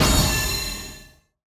sfx_reward 02.wav